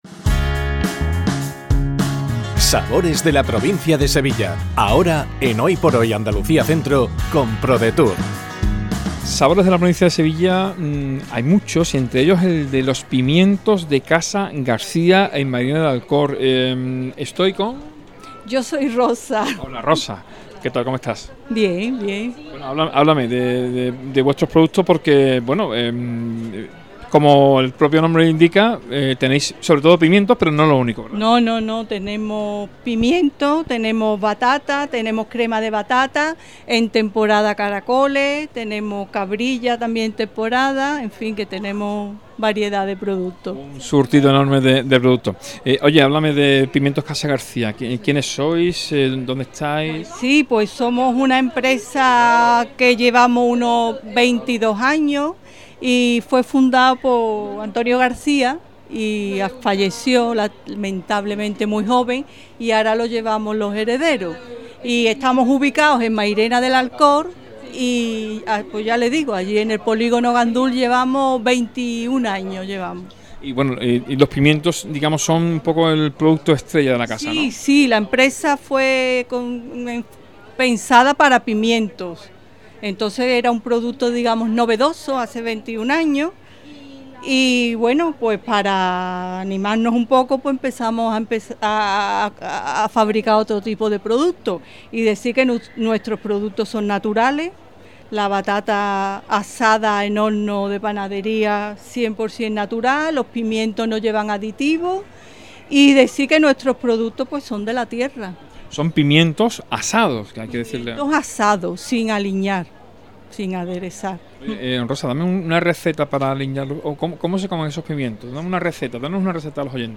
ENTREVISTA | Pimientos Casa García